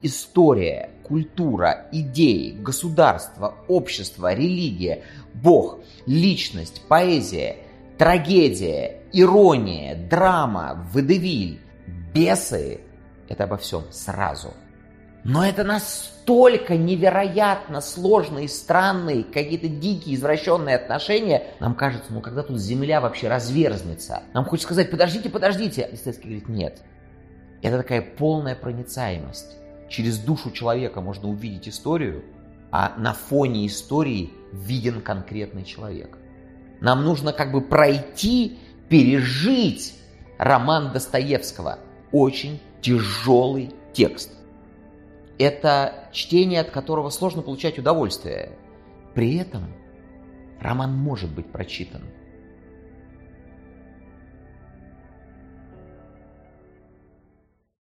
Аудиокнига Достоевский. «Бесы»: Хаос как проект | Библиотека аудиокниг